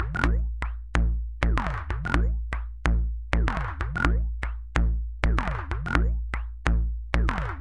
一个古怪的过滤性的循环。
Tag: 回路 打击乐器 节奏